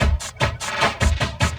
45 LOOP 01-L.wav